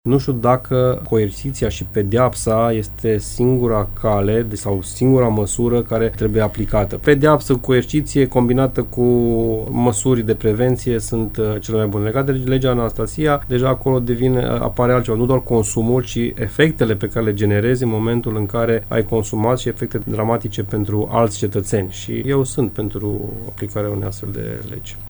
Prezent în emisiunea “Dezbaterea zilei” de la Radio Timișoara, deputatul PNL de Timiș a declarat că susține modificările legislative menite să înăsprească sancțiunile în cazul soferilor care se urcă la volan beți sau drogați.